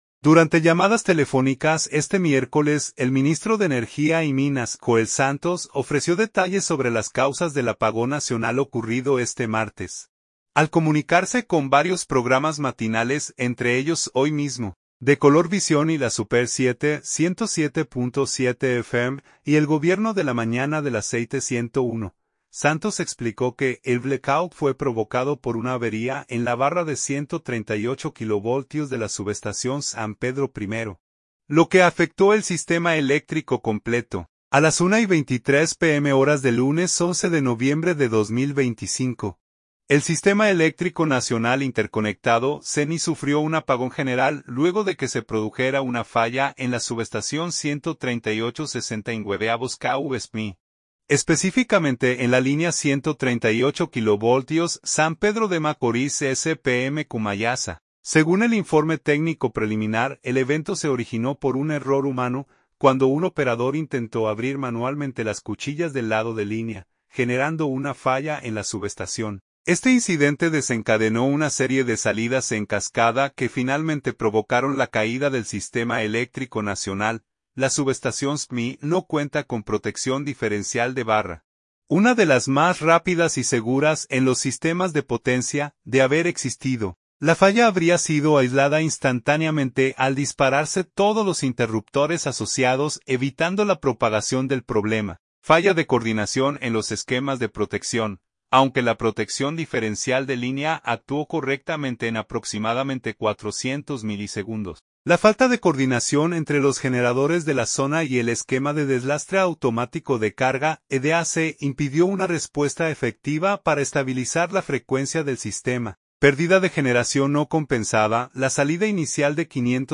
Santo Domingo.– Durante llamadas telefónicas este miércoles, el ministro de Energía y Minas, Joel Santos, ofreció detalles sobre las causas del apagón nacional ocurrido este martes, al comunicarse con varios programas matinales entre ellos Hoy Mismo, de Color Visión y la Super Siete (107.7 FM) y el Gobierno de la Mañana de la Z101.